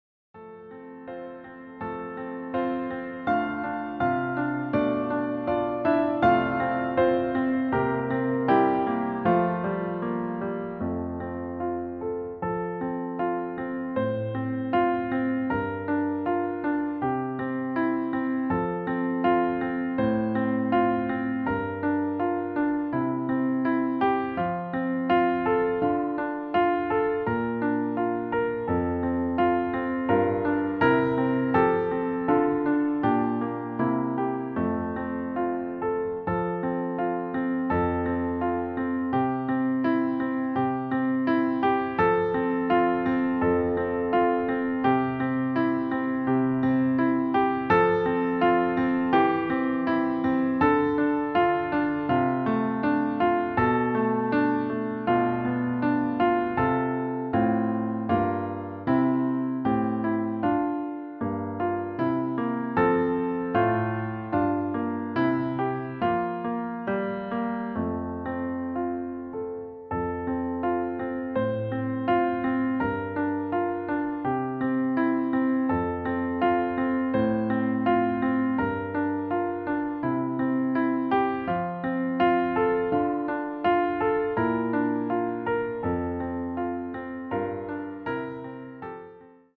Klavierversion
• Das Instrumental beinhaltet NICHT die Leadstimme
Klavier / Streicher